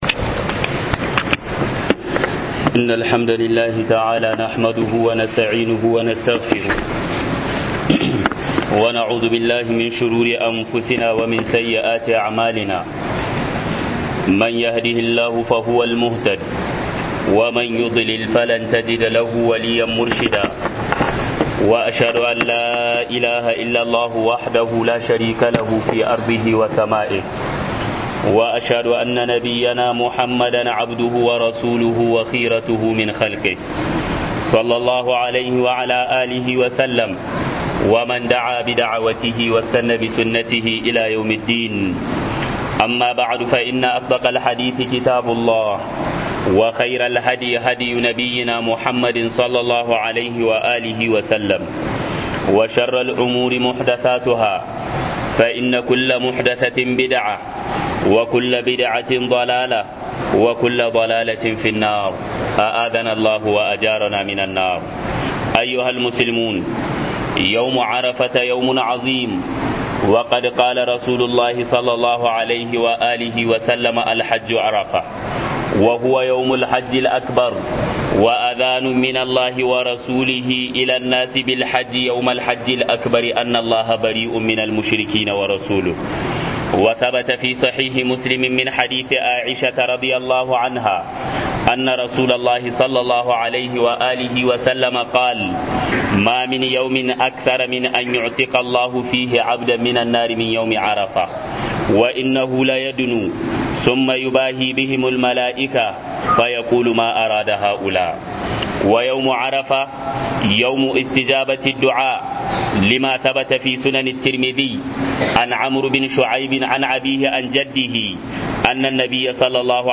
HUDUBA-RANAR-ARFA